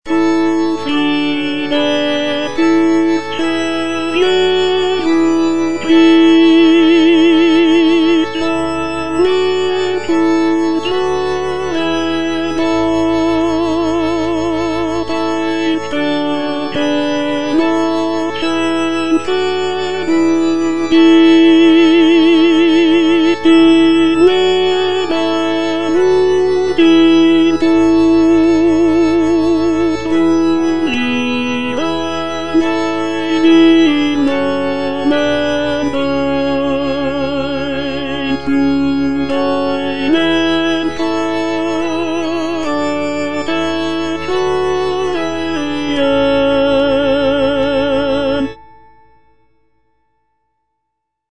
Cantata
Tenor (Voice with metronome) Ads stop